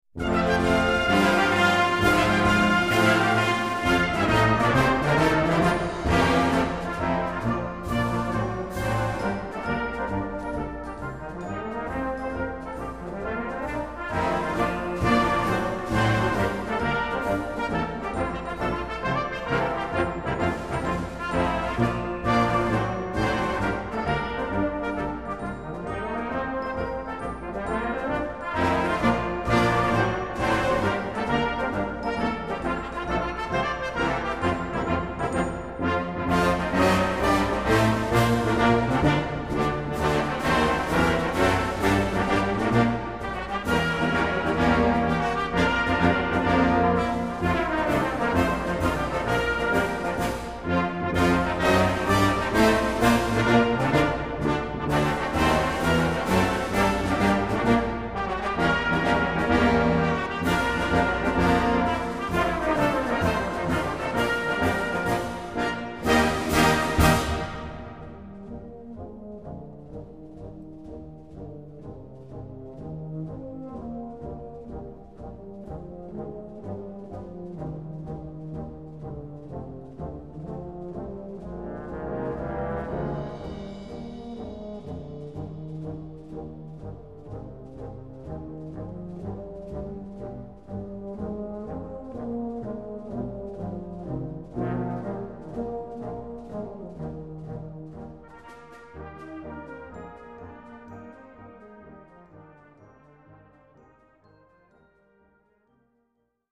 Répertoire pour Brass Band